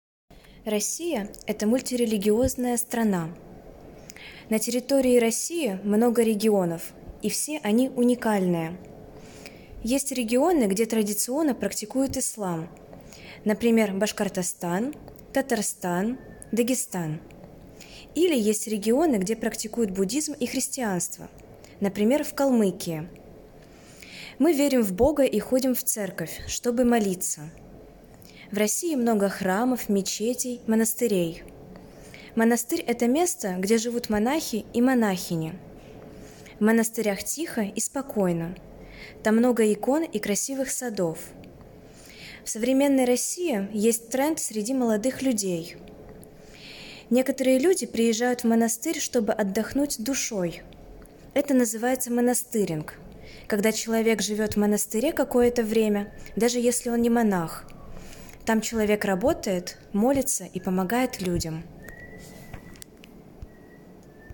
Vous trouverez ici des fichiers mp3 en 14 langues, enregistrés par des locuteurs natifs, libres de droits pour une utilisation pédagogique ou personnelle pour l'entraînement à la compréhension de l'oral en français, anglais, allemand, espagnol, italien, russe, portugais, chinois, occitan, arabe, catalan, corse, créole et hébreu